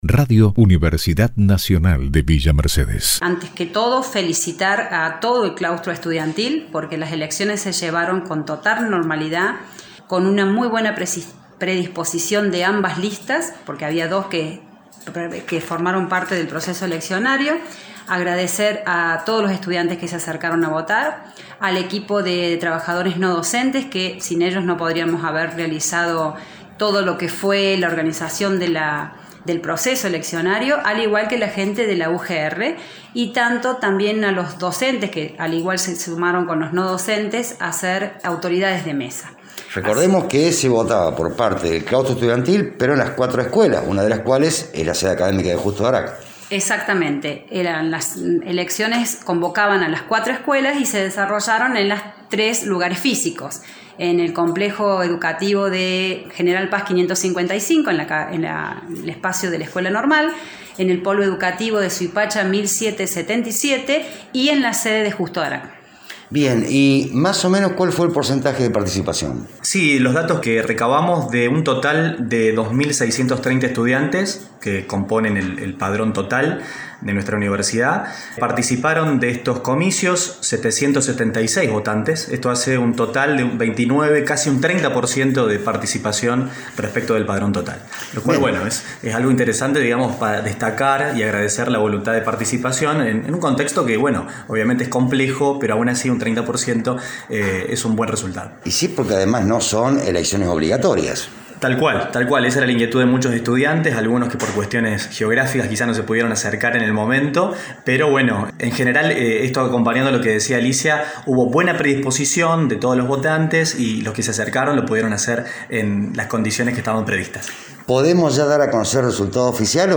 en diálogo con Radio UNViMe 93.7 MHz